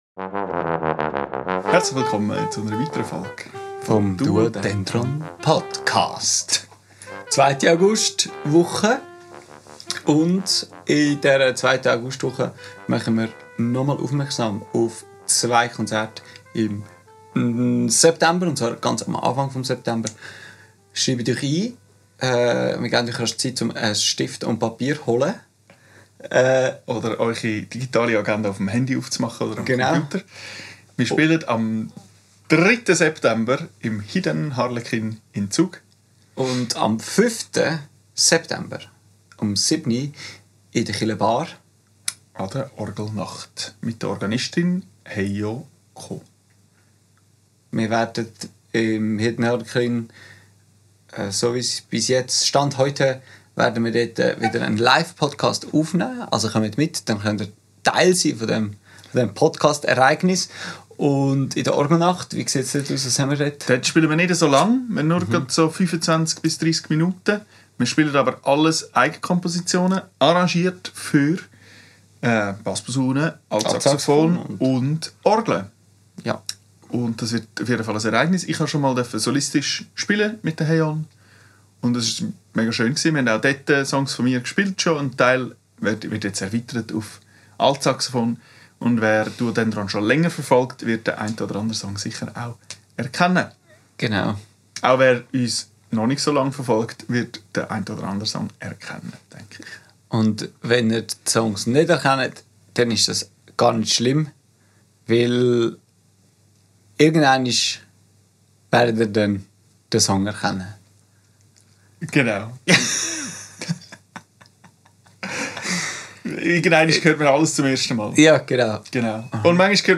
Auch die zweite Augustfolge findet wieder drinnen statt.